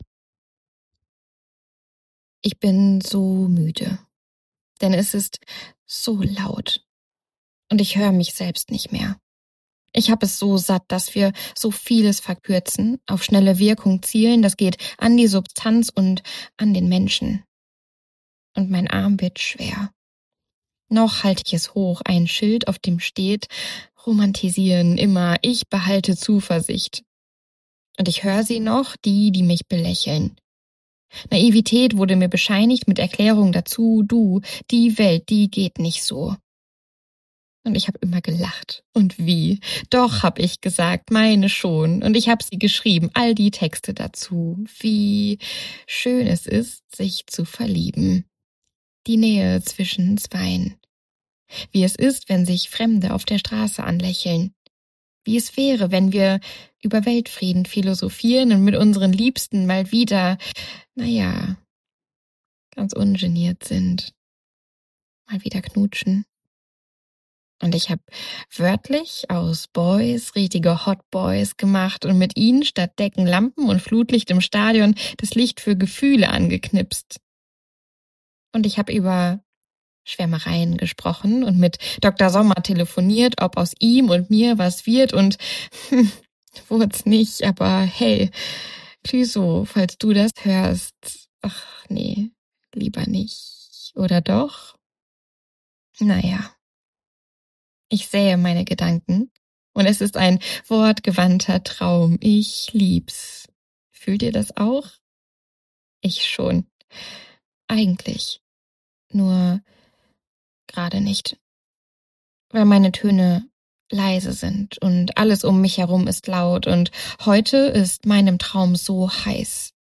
jedoch weniger frei gesprochen. Mehr geführt. Und gefühlt.